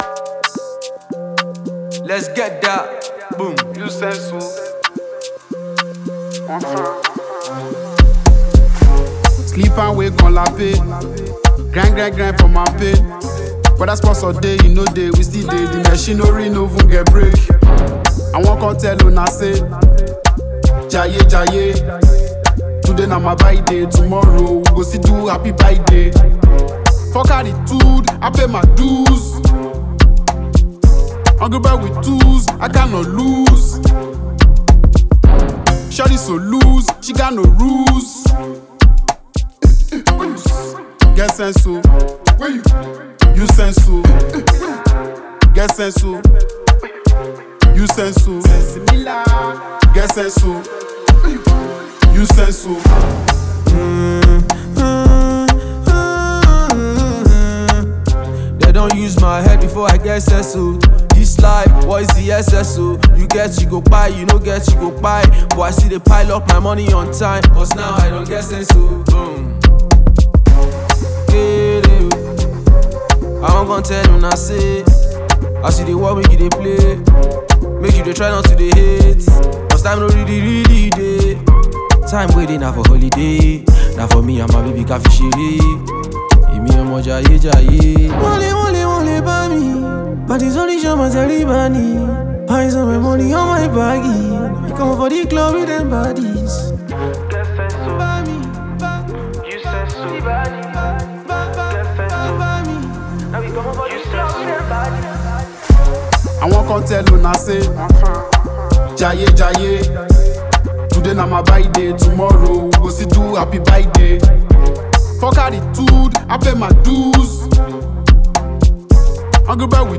Nigerian Hip-Hop and Afrotrap sensation
Their dynamic delivery and razor-sharp bars